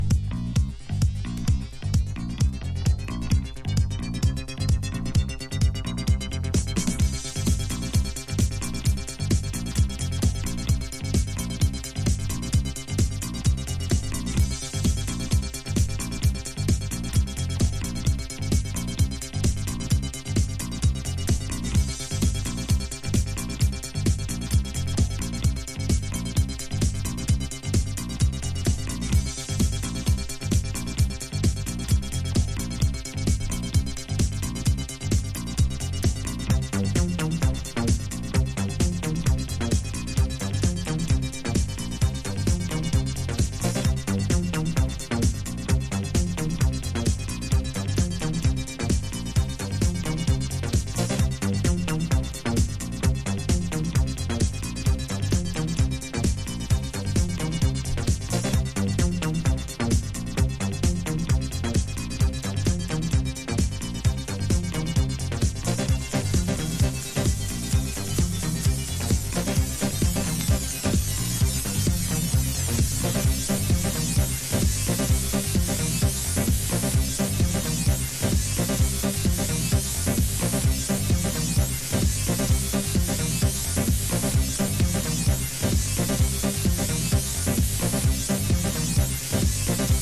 イタロ・ディスコ名曲をロング・エディット。
# NU-DISCO / RE-EDIT# ELECTRO HOUSE / TECH HOUSE